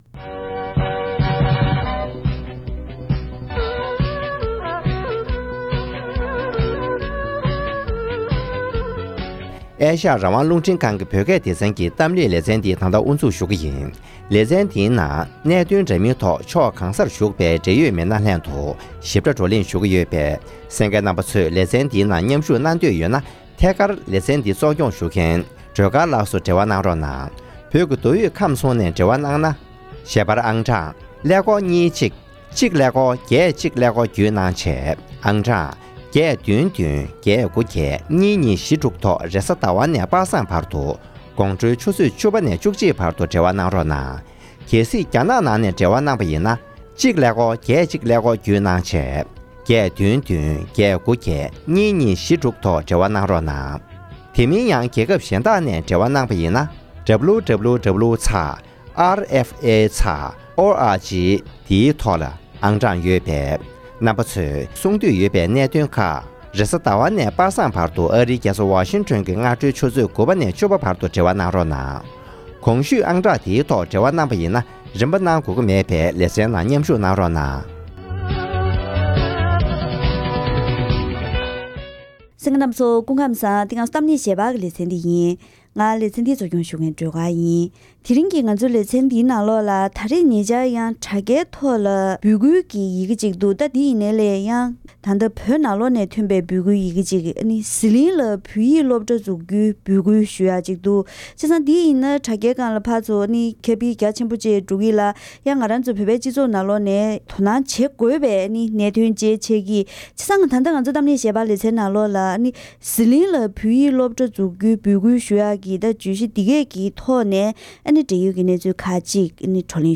༄༅། །དེ་རིང་གི་གཏམ་གླེང་ཞལ་པར་ལེ་ཚན་འདིའི་ནང་ཟི་ལིང་ནང་དུ་བོད་ཡིག་སློབ་གྲྭ་འཛུགས་རྒྱུའི་འབོད་སྐུལ་བྱེད་བཞིན་ཡོད་པའི་ཐོག་ནས་བོད་ནང་གི་བོད་སྐད་ཡིག་སློབ་གསོའི་སྐོར་ལ་འབྲེལ་ཡོད་མི་སྣ་ཁག་ཅིག་ལྷན་བཀའ་མོལ་ཞུས་པ་ཞིག་གསན་རོགས་གནང་།